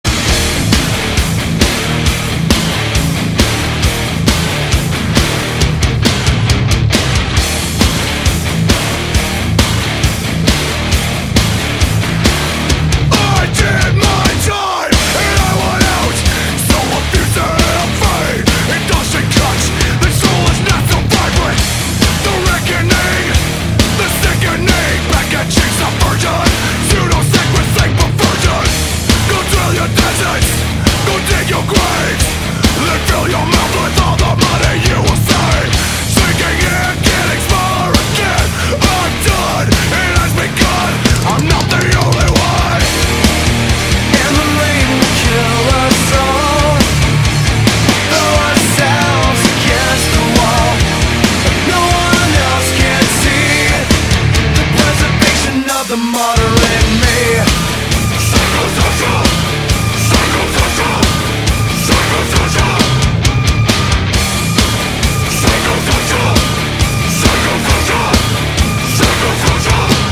жесткие
мощные
Драйвовые
Alternative Metal
nu metal
гроулинг